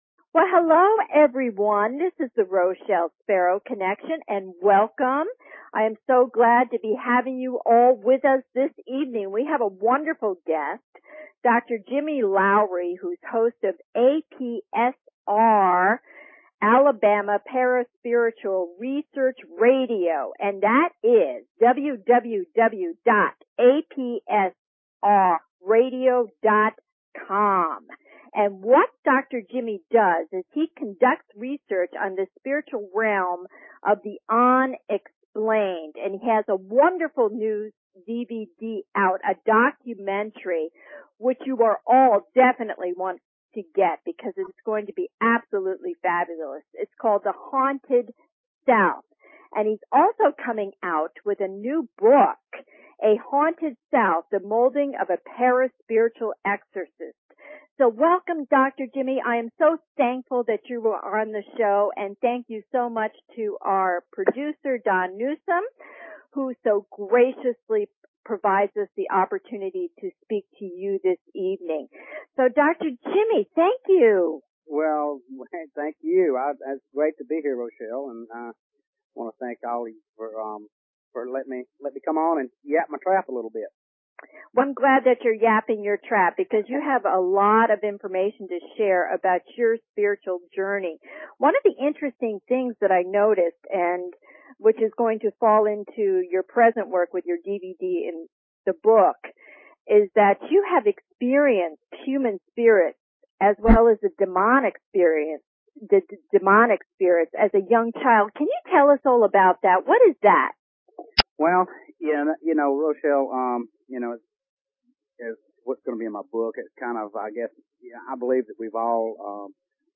Talk Show Episode, Audio Podcast, Psychic_Connection and Courtesy of BBS Radio on , show guests , about , categorized as